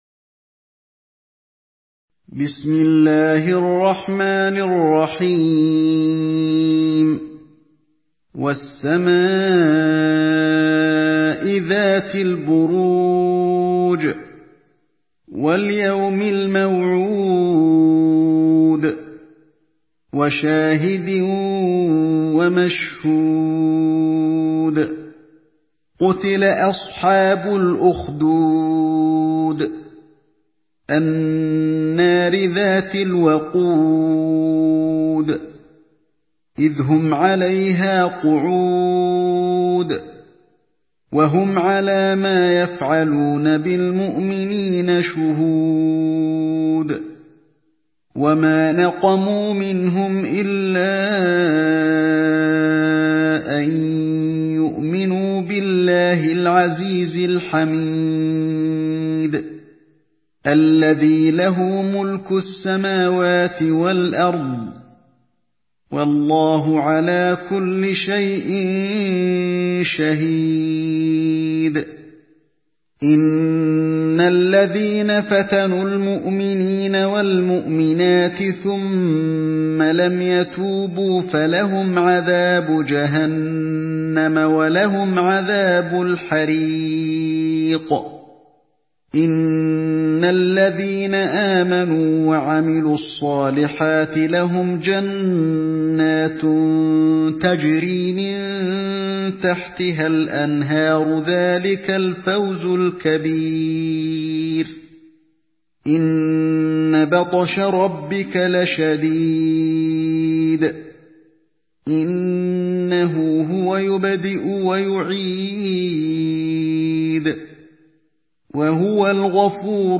Surah Al-Buruj MP3 by Ali Alhodaifi in Hafs An Asim narration.
Murattal